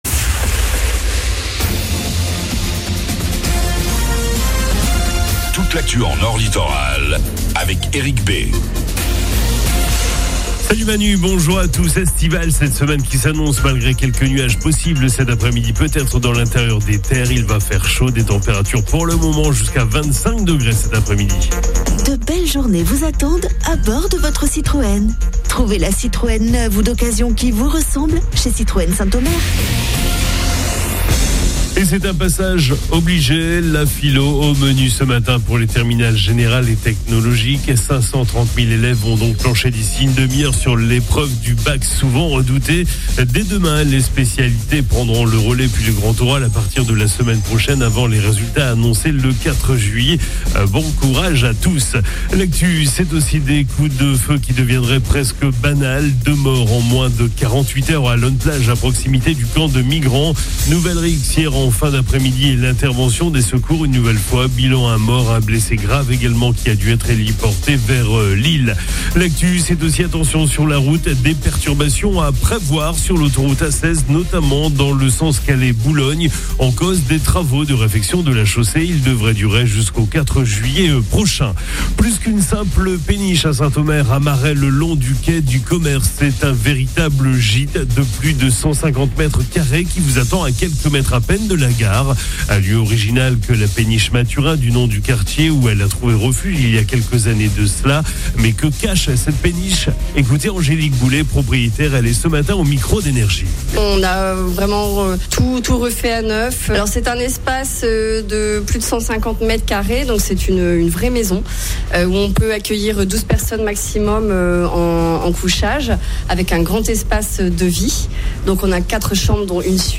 FLASH 16 JUIN 25